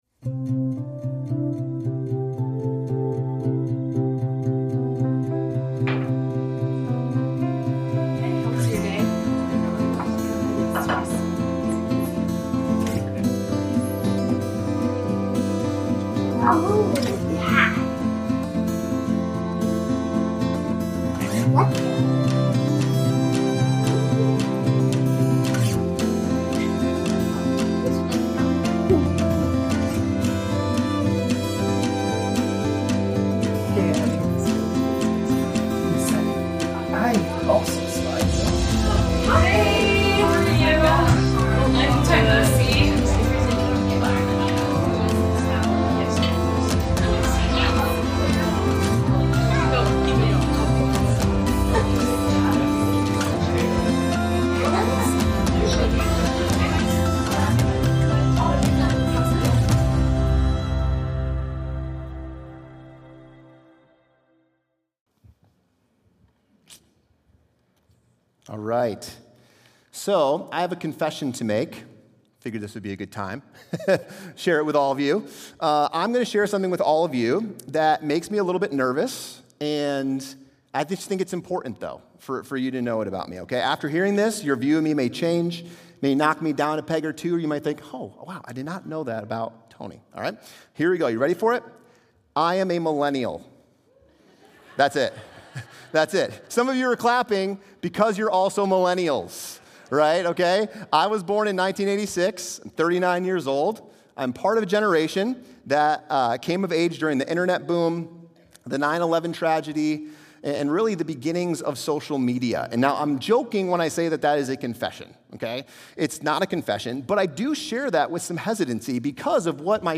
The sermon encourages building relationships, honoring one another, and loving deeply across generations within the church.